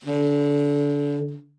Index of /90_sSampleCDs/Best Service - Brass Super Section/TENORSAXSOFT